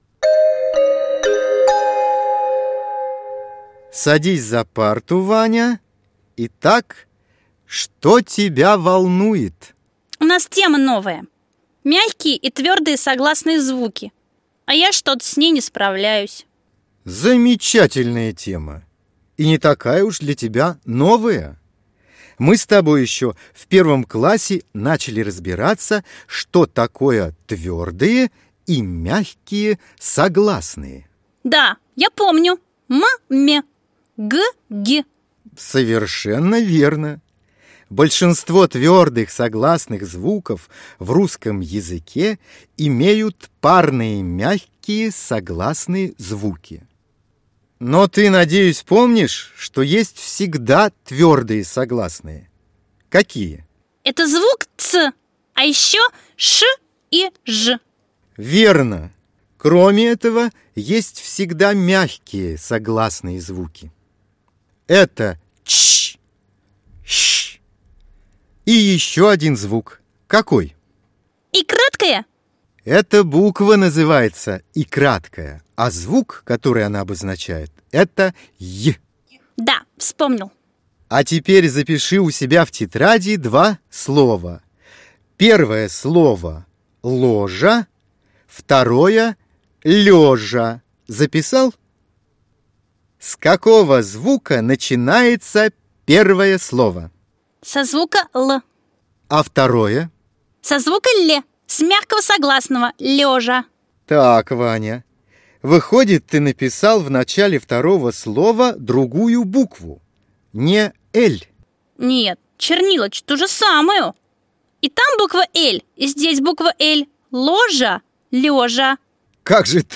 Аудиокнига Согласные, предложение и текст | Библиотека аудиокниг